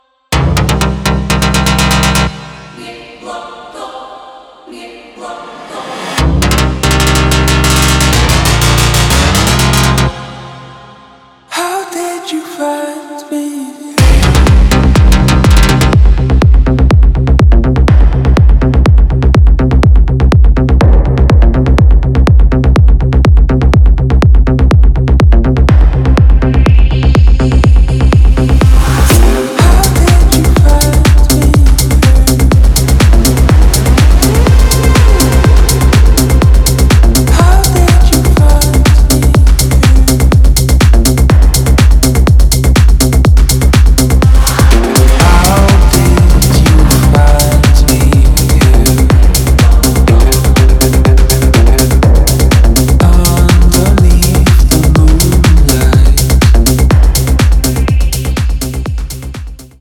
громкие
club
progressive house
Trance
Melodic house